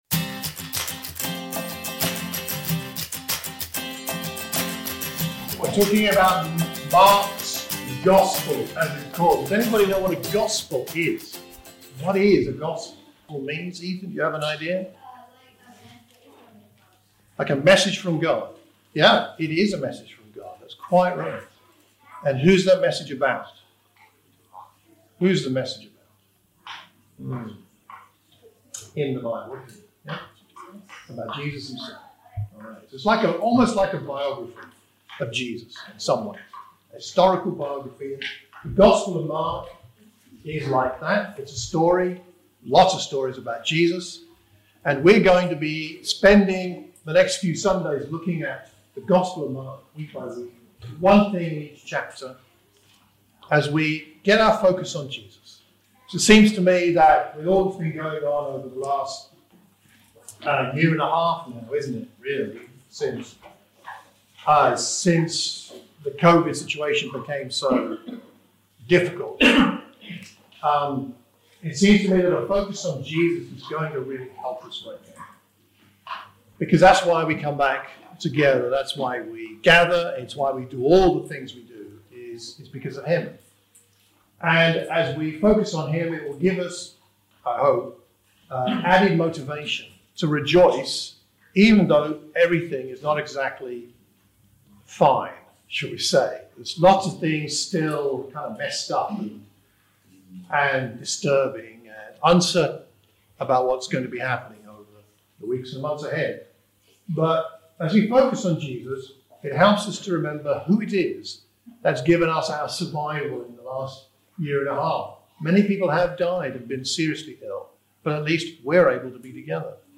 This is a sermon preached for the Watford Church of Christ.